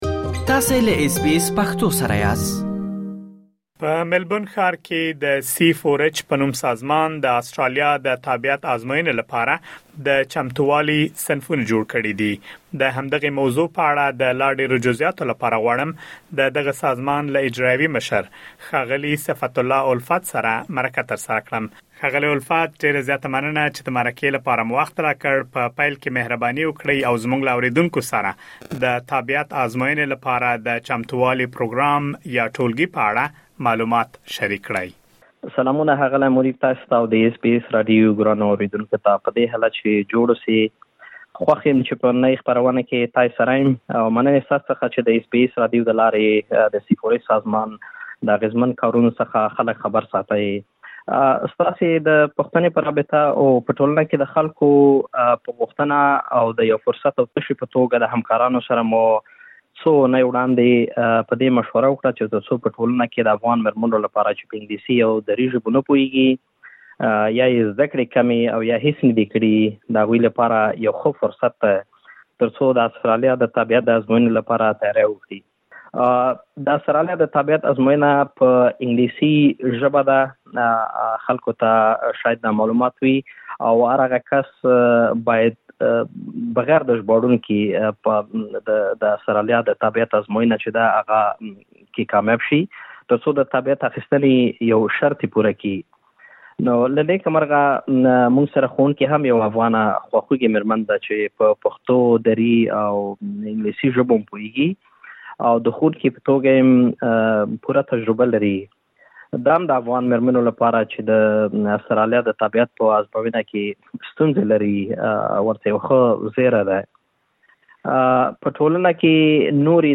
مرکه ترسره کړې.